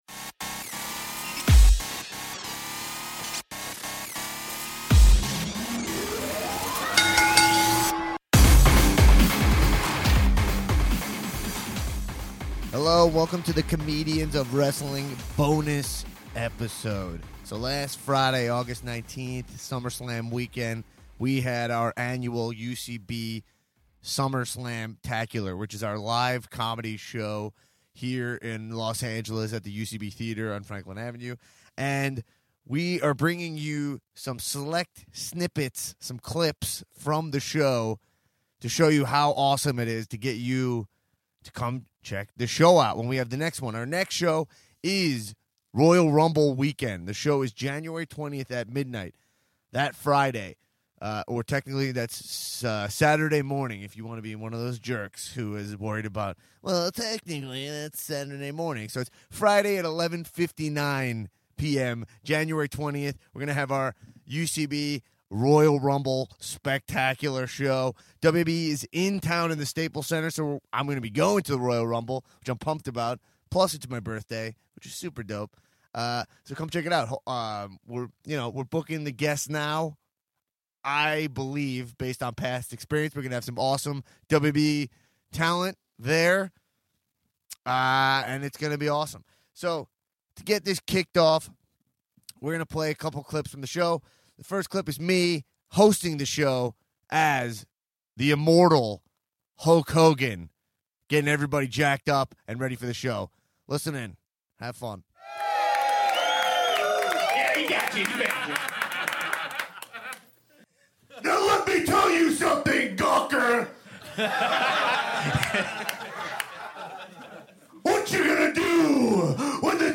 This week the Comedians of Wrestling presents: the UCB Summer Slamtacullar Special!